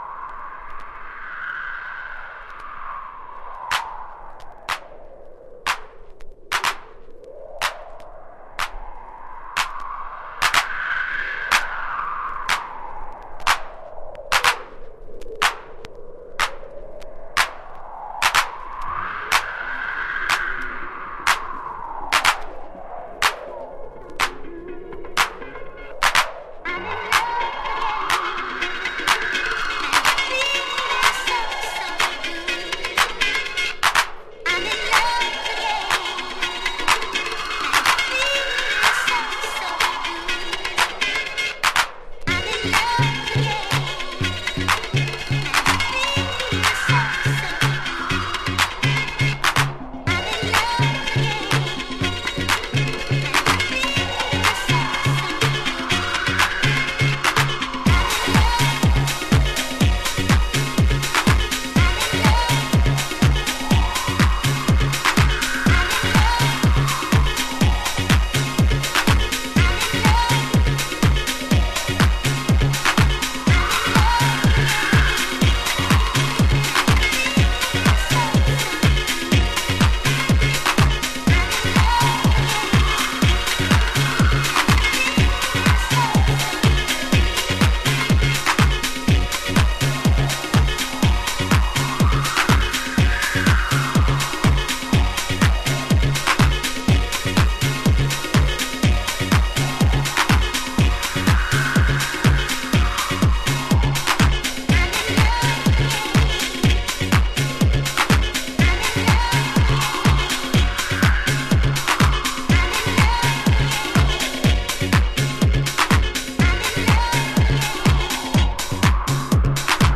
Early House / 90's Techno
センスと美学が問われるシンプルなサンプリングハウス、NYとは少し違った荒々しさを感じるCHI流ミニマルグルーヴ。